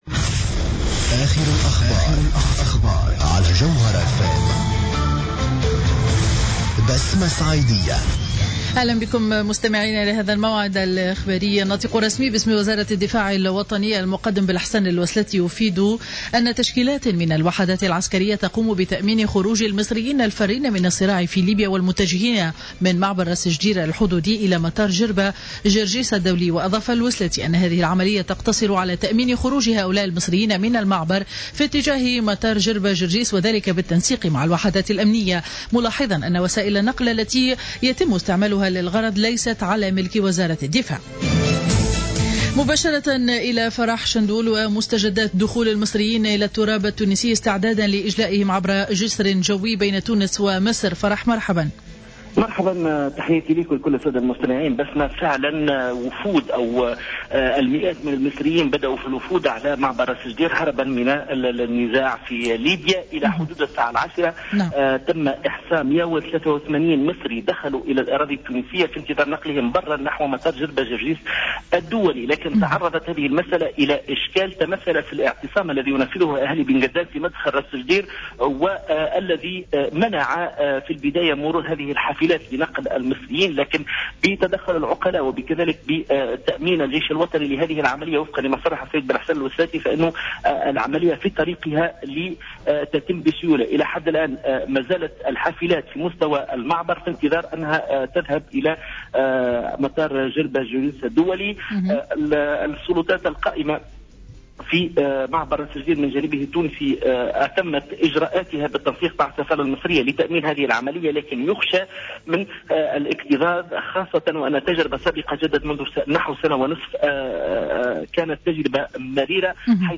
نشرة أخبار منتصف النهار ليوم الجمعة 20 فيفري 2015